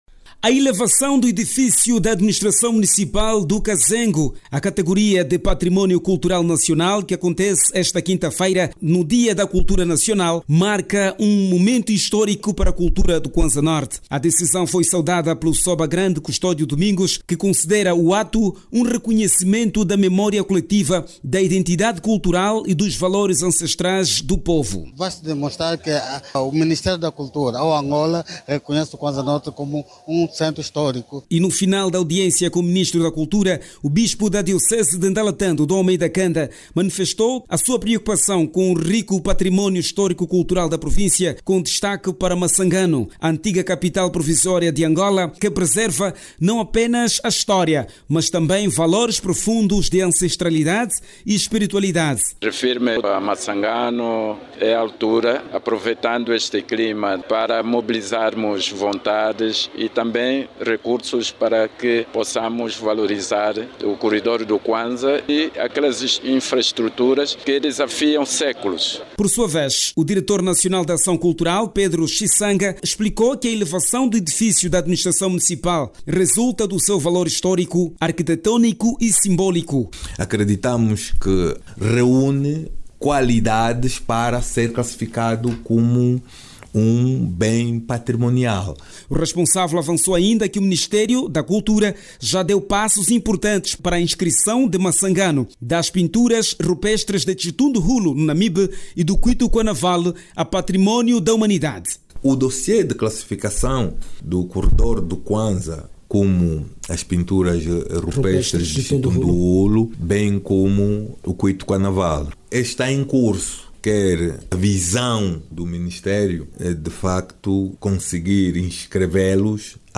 A Cidade de Ndalatando, na Província do Cuanza-Norte acolhe hoje, quinta-feira, o acto Central do 8 de Janeiro, dia da Cultura Nacional, a ser orientado pelo Ministro da Cultura, Filipe Zau. Da agenda destaque para a elevação a património cultural nacional, o actual edifício da Administração Municipal de Cazengo. As autoridades tradicionais do município do Cazengo, realçam que com este momento fica para a memória colectiva a ancestralidade da infraestrutura. Ouça no áudio abaixo toda informação com a reportagem